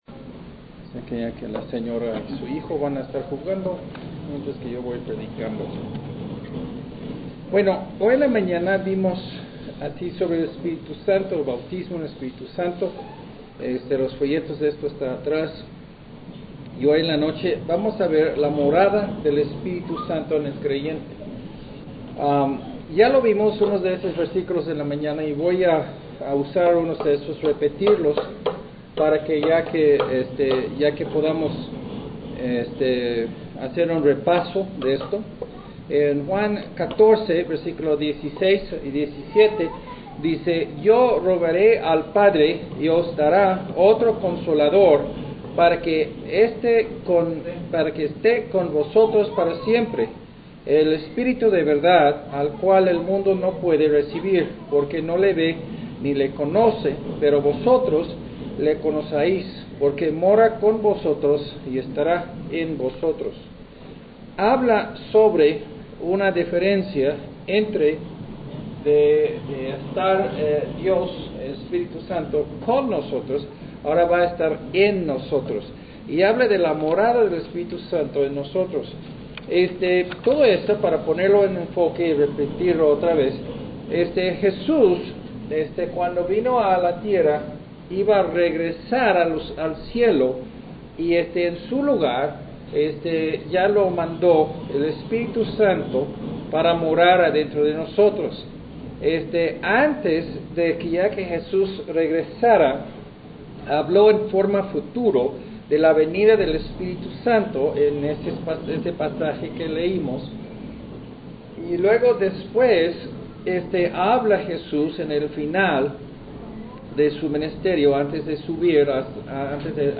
doct19 Morada del Espíritu Santo Sermón en Audio